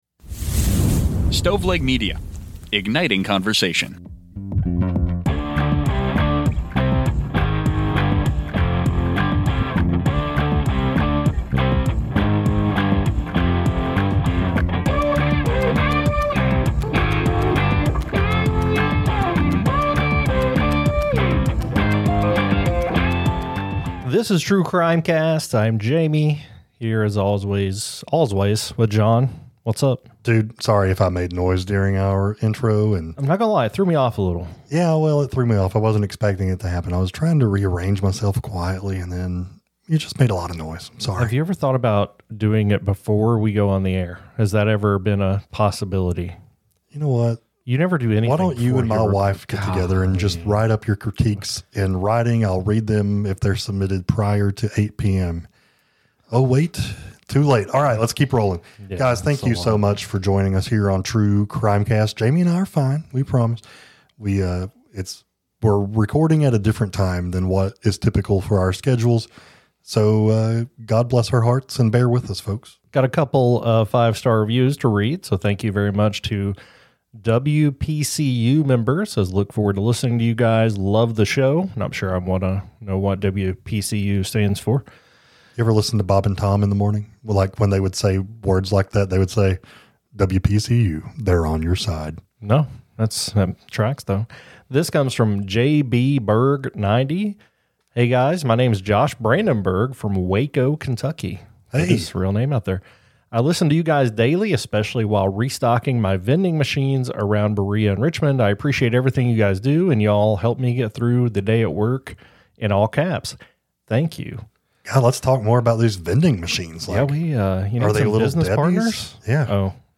True Crime, Society & Culture, Documentary, Personal Journals